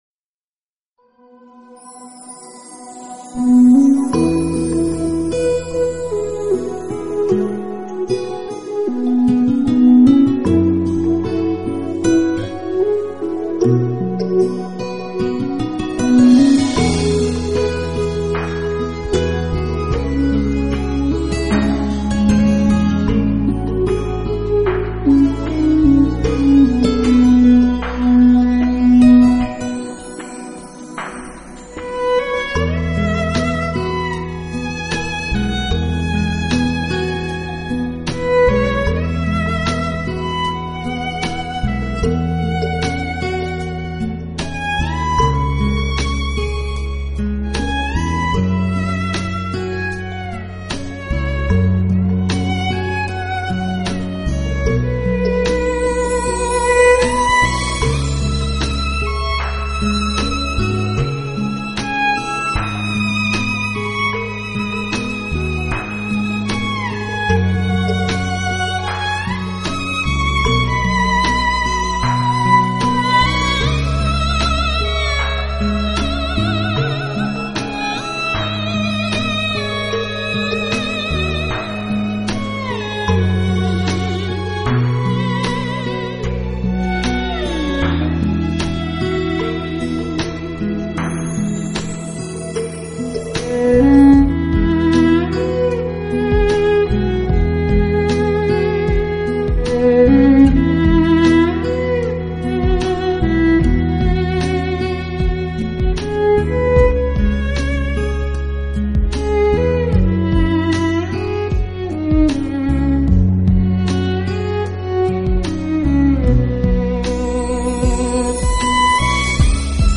小提琴演奏
旋律温婉悦耳圆润